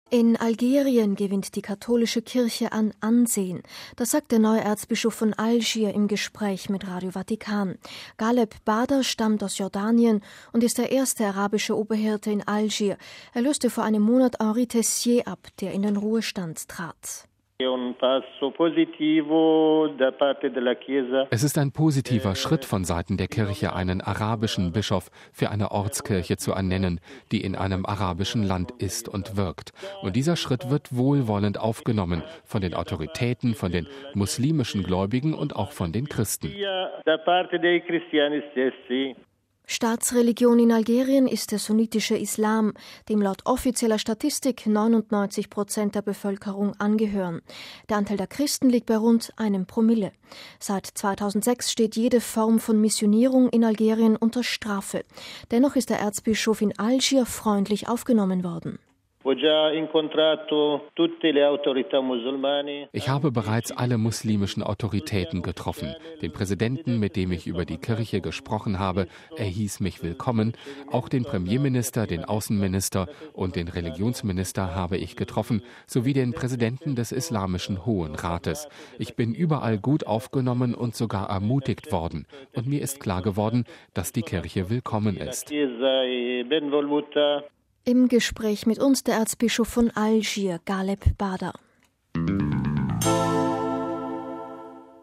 MP3 In Algerien gewinnt die katholische Kirche an Ansehen. Das sagt der neue Erzbischof von Algier im Gespräch mit Radio Vatikan.